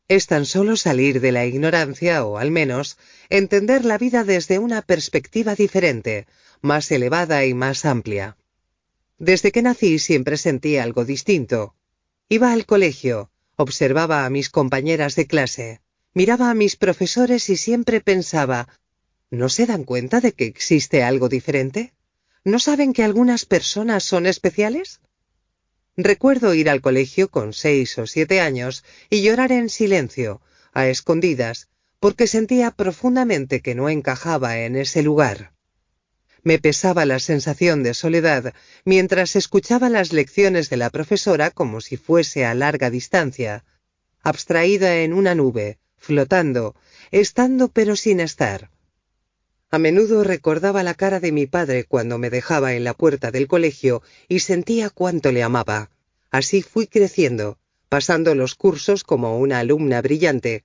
audiolibro Atrevete a ser tu maestro Suzanne Powell